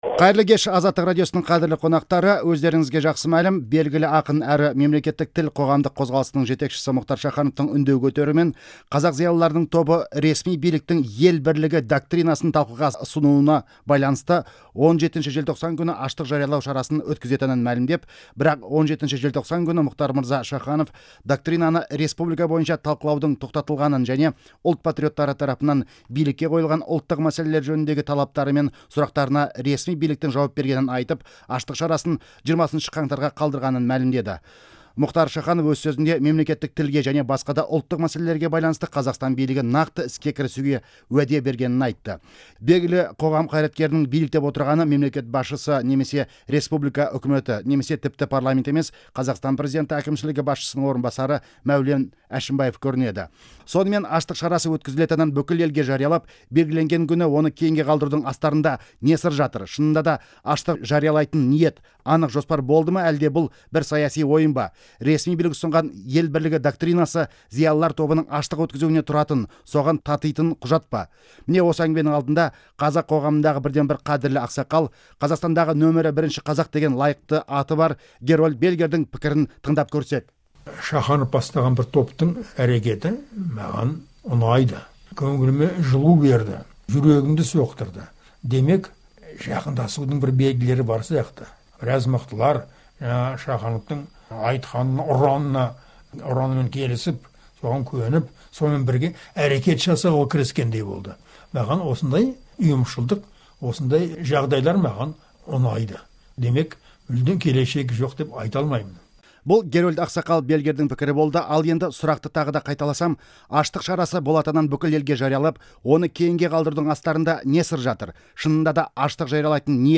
Дөңгелек үстел жазбасын осы жерден тыңдаңыз.MP3